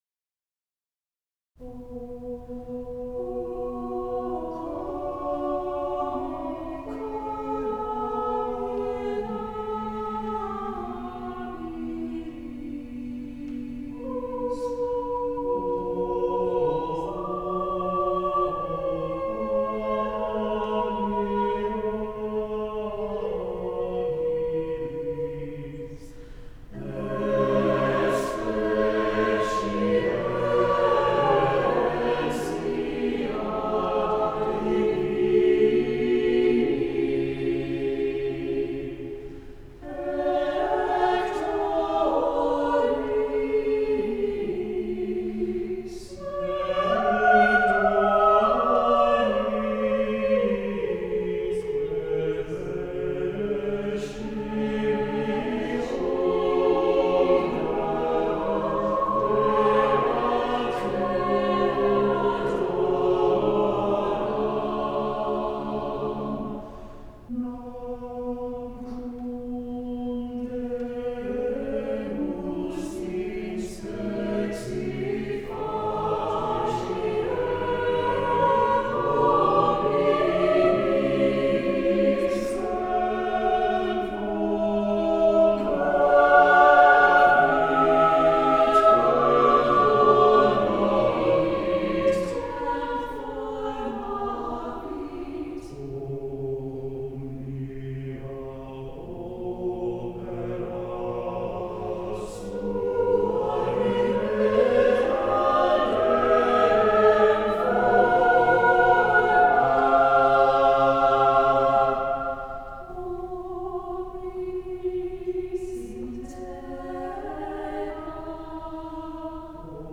SATB unaccompanied    (c. 3:20)
set in a Renaissance motet style